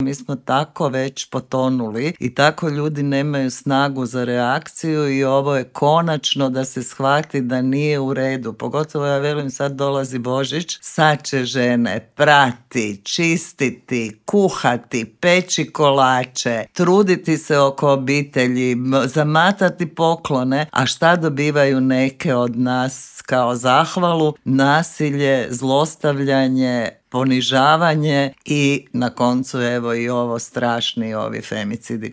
Intervjuu Media servisa